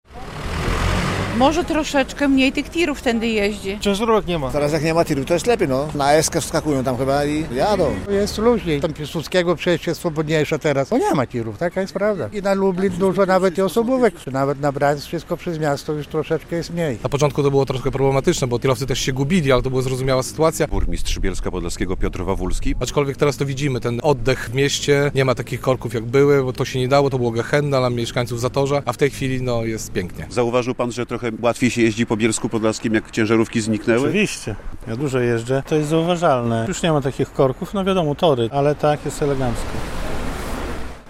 Jest spokojniej, ale do ideału jeszcze trochę brakuje - mówią mieszkańcy Bielska Podlaskiego dwa tygodnie po otwarciu obwodnicy ich miasta.
Radio Białystok | Wiadomości | Wiadomości - Dwa tygodnie po otwarciu obwodnicy - Bielsk Podlaski odczuwa poprawę